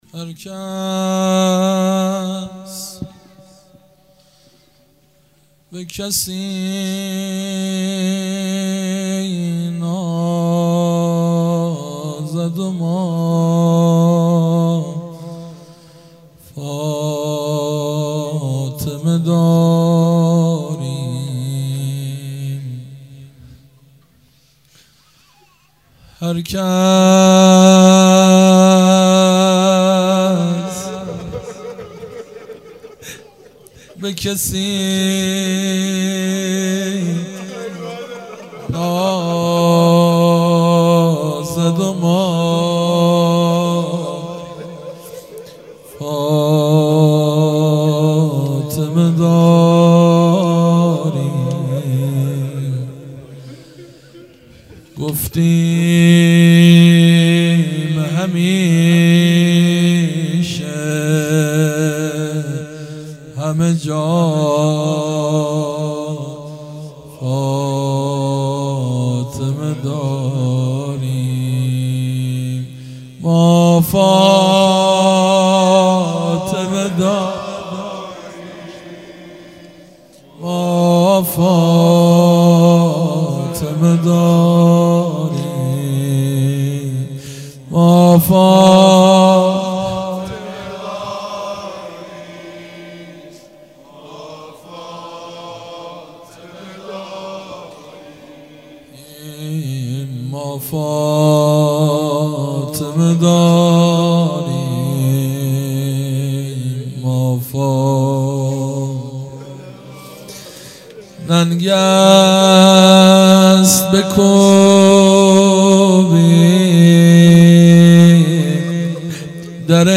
هیئت مکتب الزهرا(س)دارالعباده یزد
0 0 روضه | هرکس به کسی نازد و ما فاطمه داریم مداح